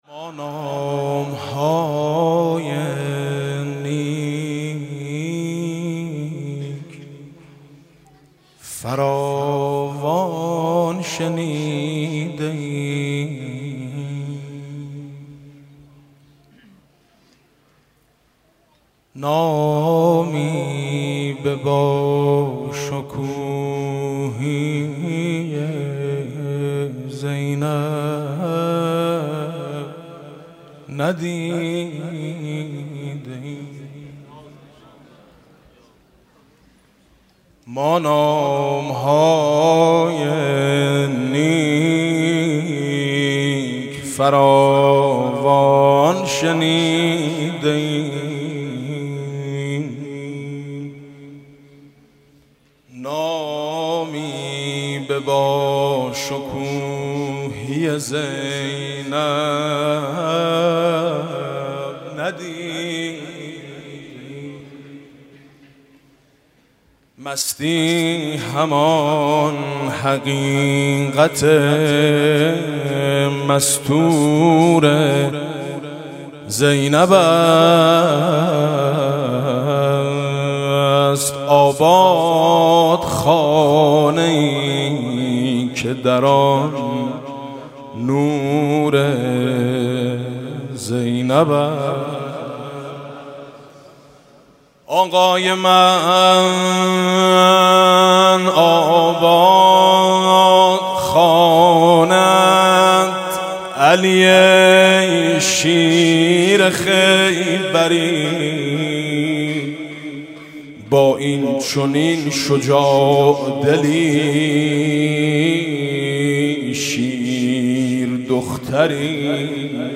مداحی شنیدنی حاج میثم مطیعی در میلاد حضرت زینب(س)